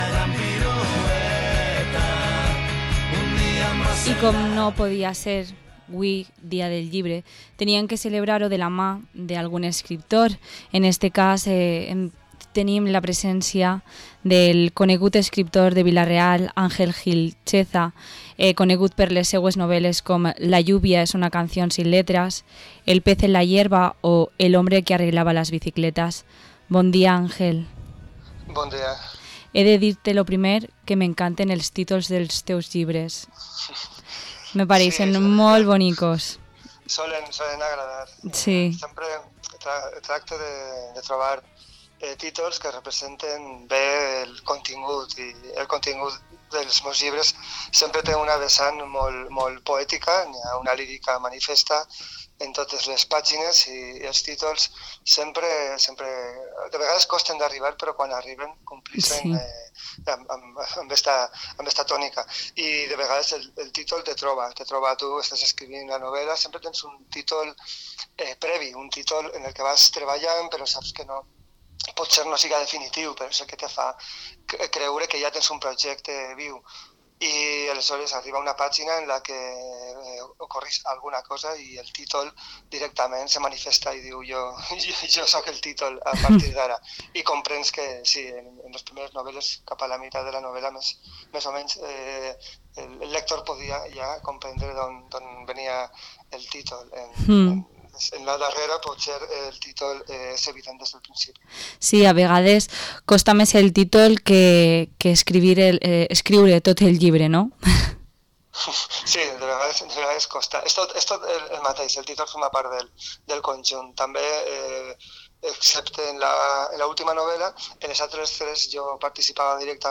Entrevista al escritor de Vila-real